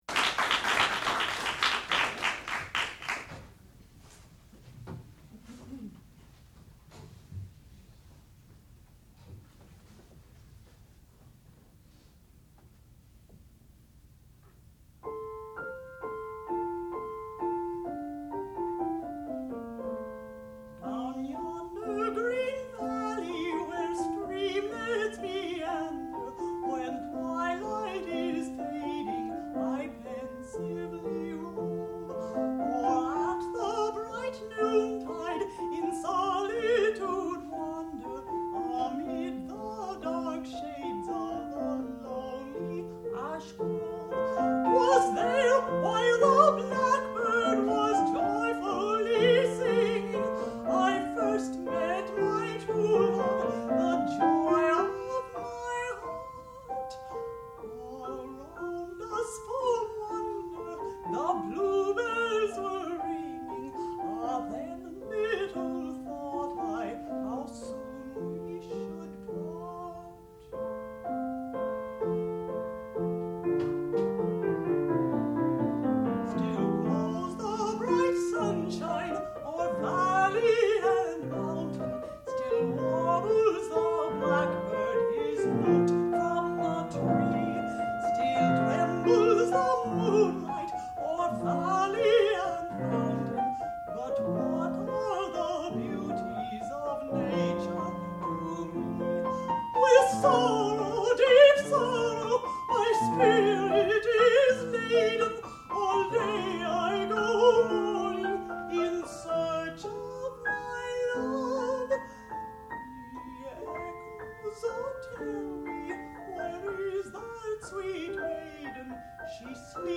sound recording-musical
classical music
Master's Degree Recital
contralto